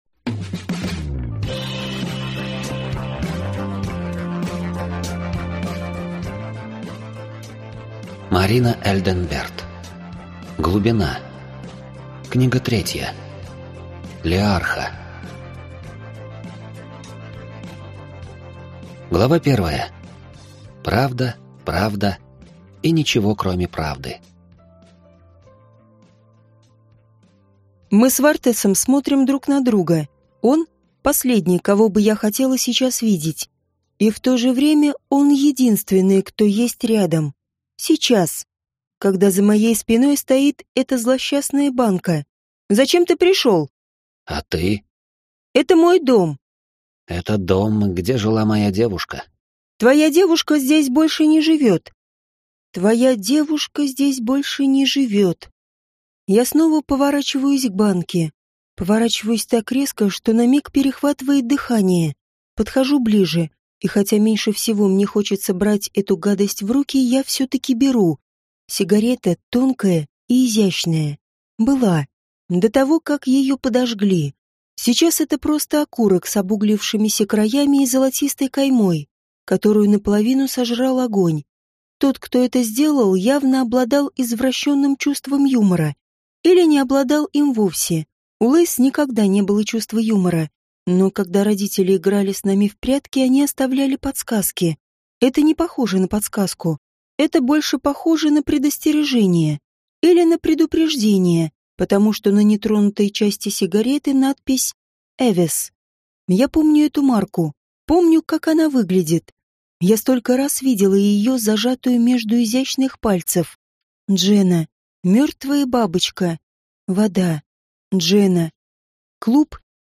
Аудиокнига Лиарха | Библиотека аудиокниг
Прослушать и бесплатно скачать фрагмент аудиокниги